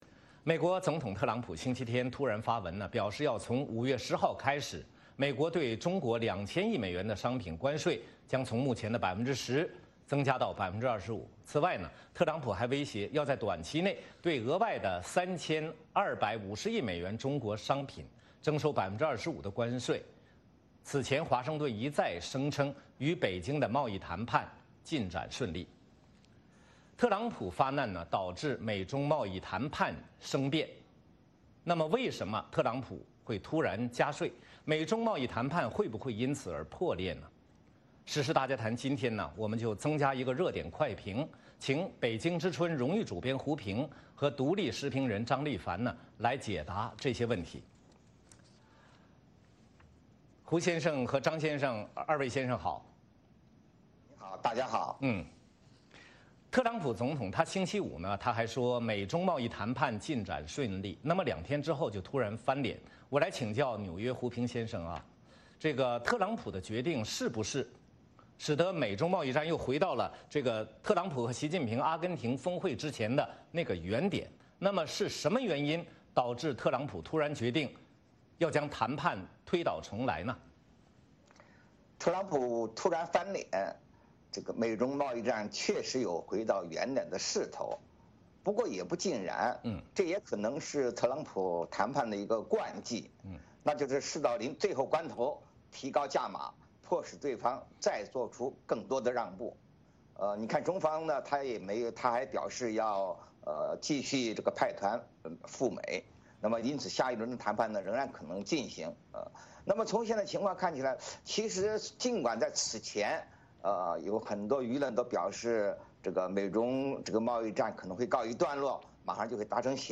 时事大家谈是一个自由论坛。